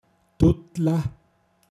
Wortlisten - Pinzgauer Mundart Lexikon
Kalb, das noch an der Mutterkuh säugt Tuttla, m.